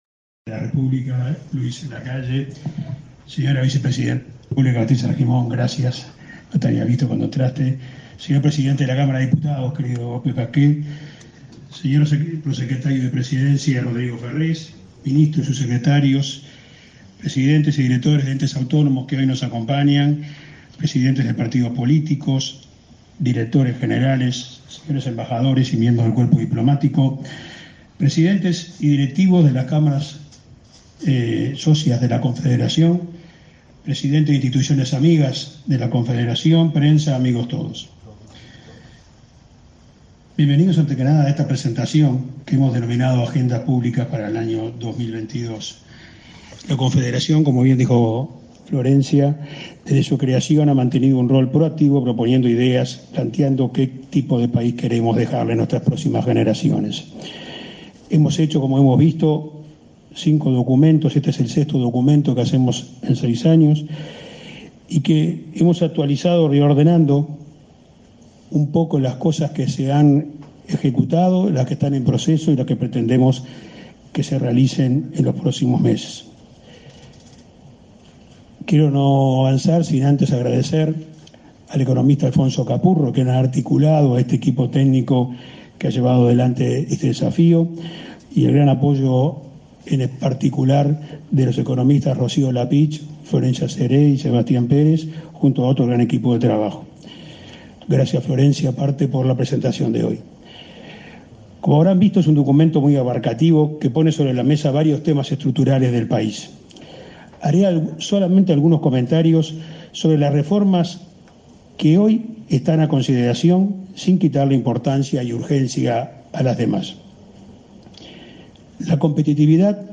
El presidente de la República, Luis Lacalle Pou, concurrió, este 12 de diciembre, a la presentación de un balance técnico y propuestas para 2022 de la Confederación de Cámaras Empresariales.